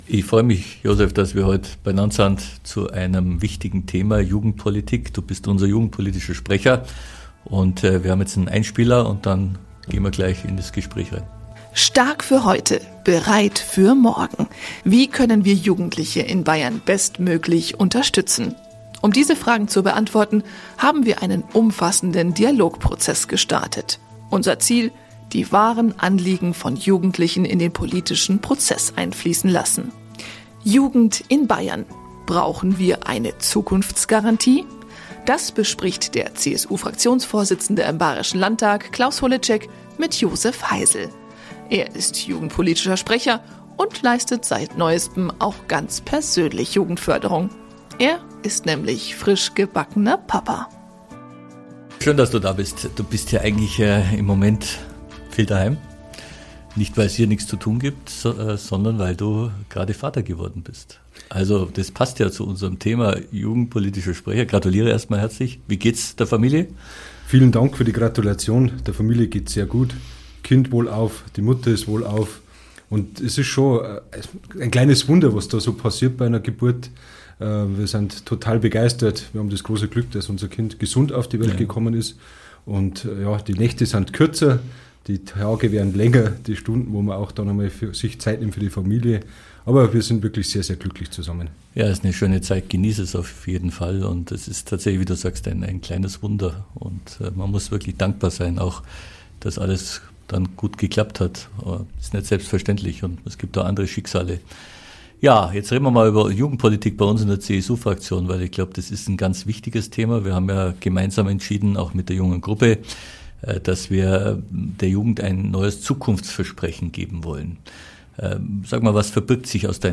In der aktuellen Folge sprechen unser Fraktionsvorsitzender Klaus Holetschek und unser jugendpolitischer Sprecher Josef Heisl über die Zukunft der Jugend. In den kommenden Wochen möchten wir den Dialog mit der Jugend intensivieren und aktiv in den Austausch treten.